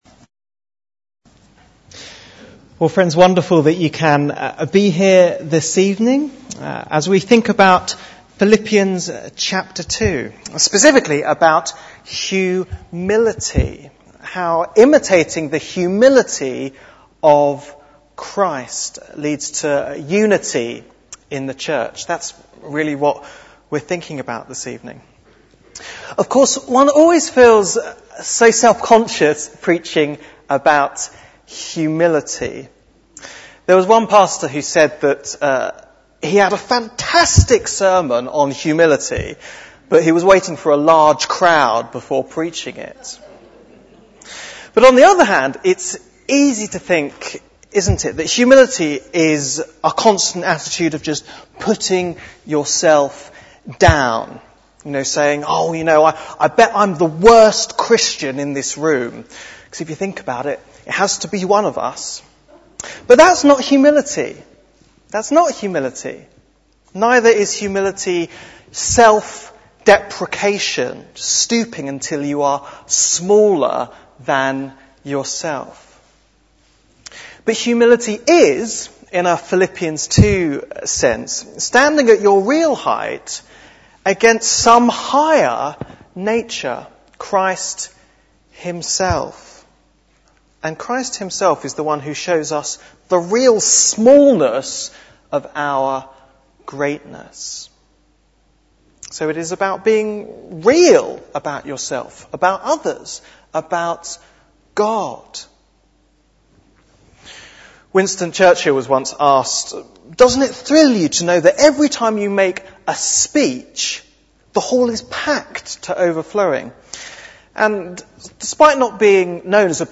Bible Text: Philippians 2:1-11 | Preacher